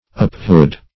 Apehood \Ape"hood\, n. The state of being an ape.